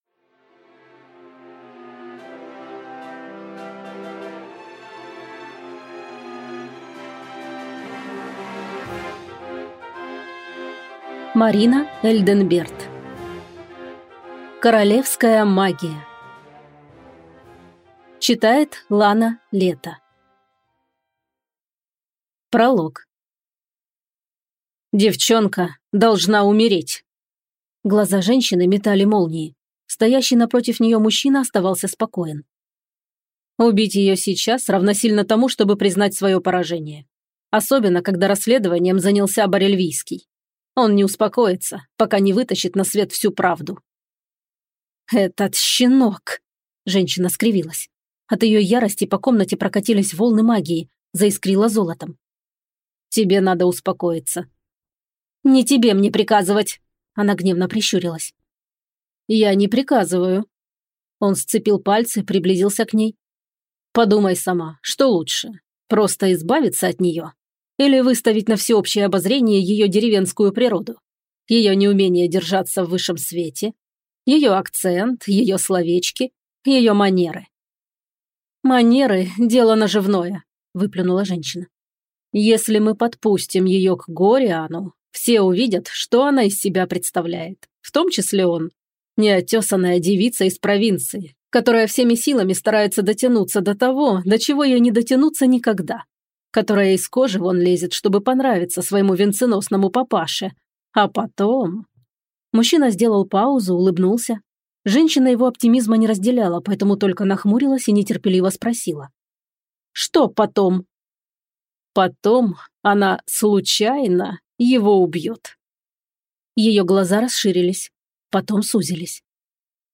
Аудиокнига Королевская магия | Библиотека аудиокниг